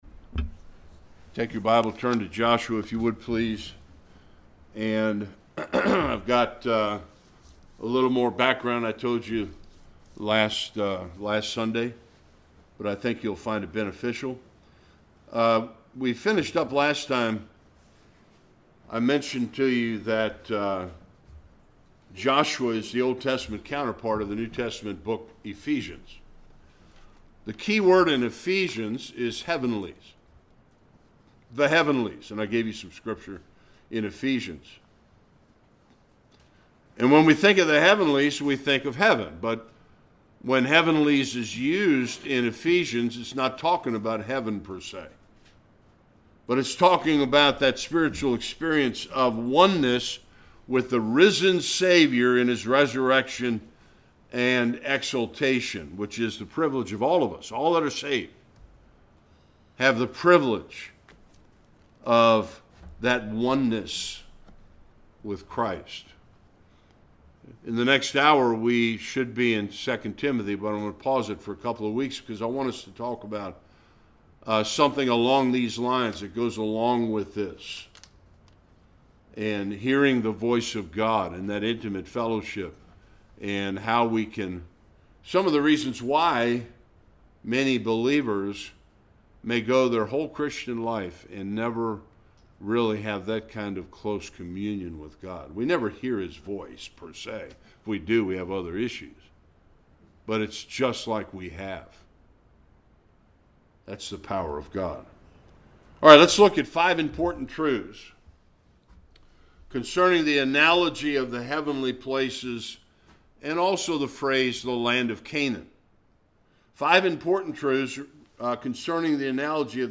Various passages Service Type: Sunday School We will conclude the introduction to the book of Joshua.